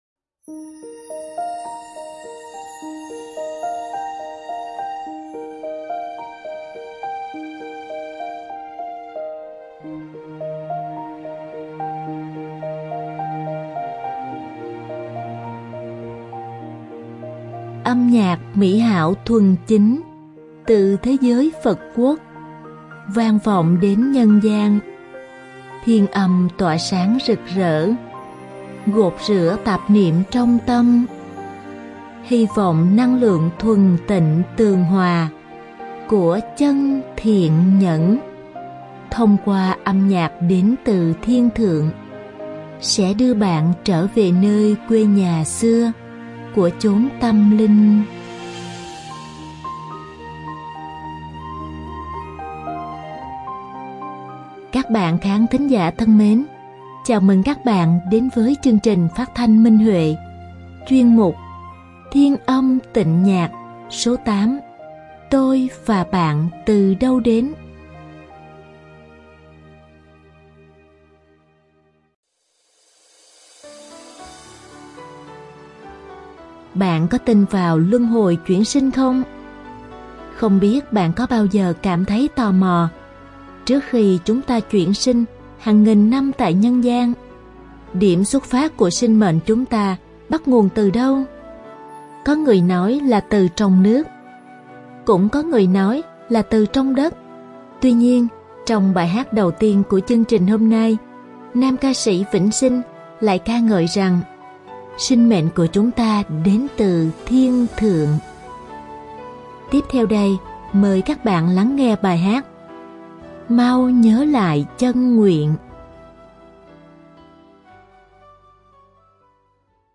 Đơn ca nam